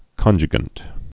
(kŏnjə-gənt)